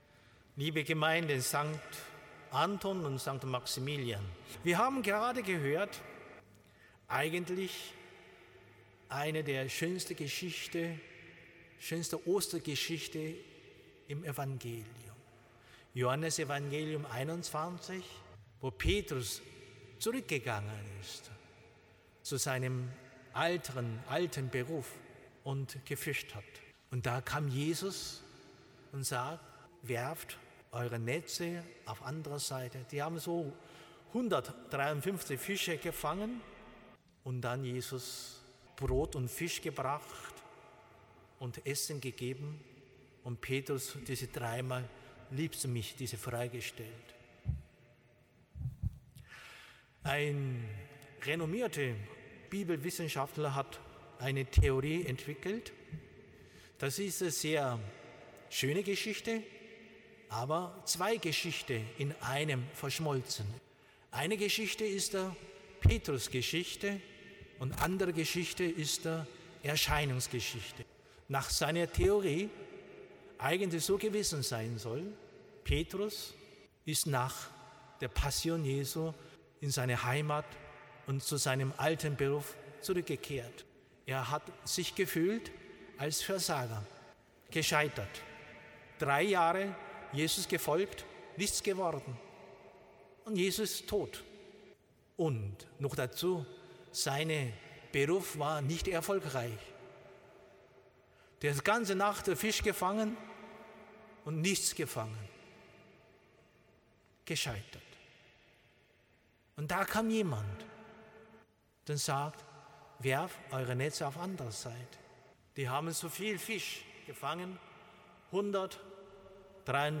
Audiomitschnitt der Predigt vom 4. Mai 2025 in der Antoniuskirche mit Erstkommunionkindern von St. Maximilian